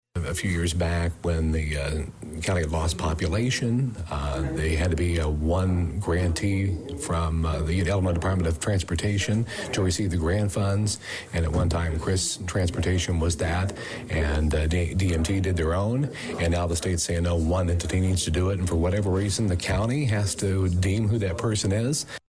As County Board Chairman Larry Baughn explained after Wednesday (May 22nd) evening’s meeting; both Danville Mass Transit (DMT), and the CRIS Rural Mass Transit District of Vermilion County will continue doing what they do best.